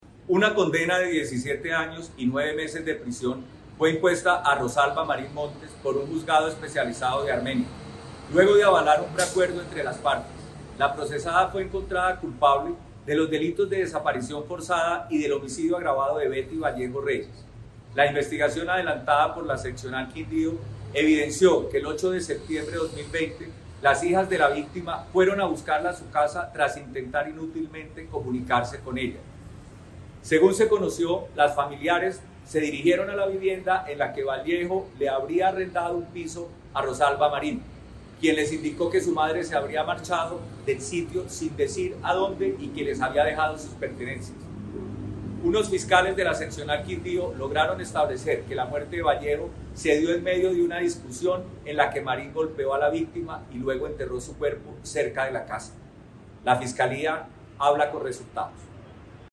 Audio: Director seccional de fiscalias Quindío, Dr Justino Hernandez Murcia